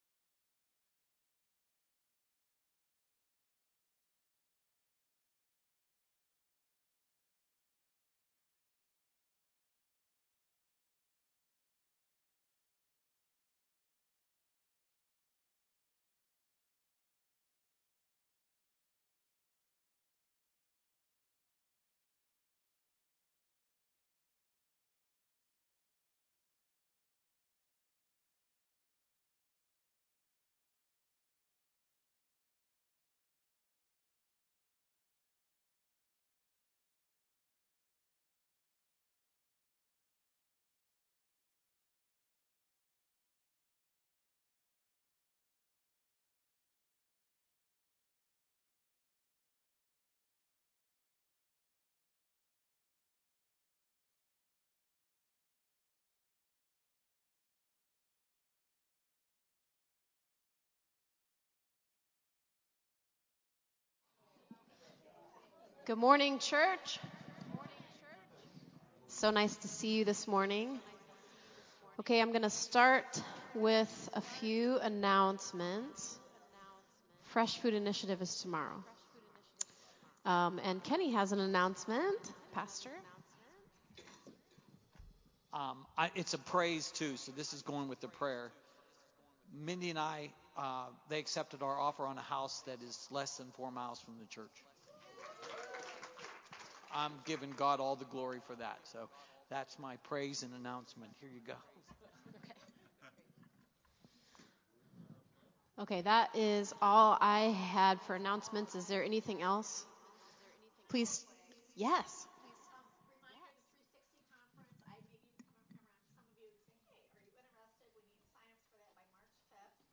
A wonderful set of songs from our worship team this Sunday.